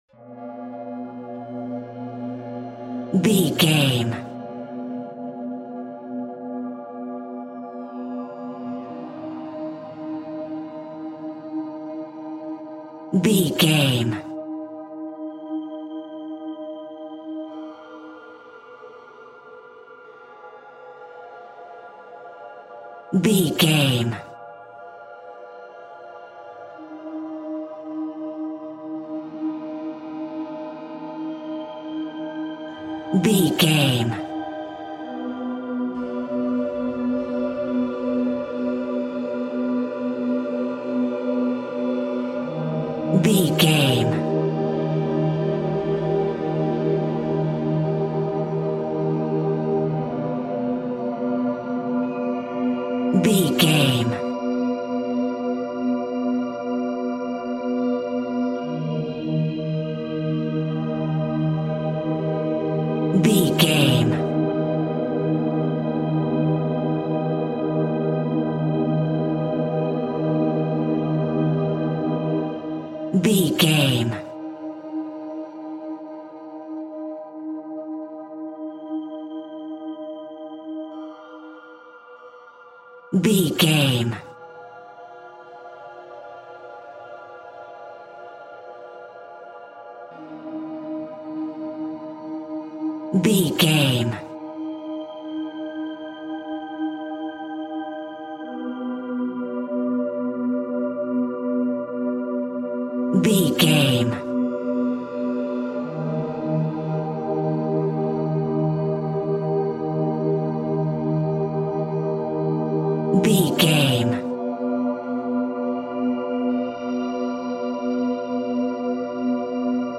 Thriller
Atonal
Slow
tension
ominous
dark
haunting
eerie
Horror synth
Horror Ambience
synthesizer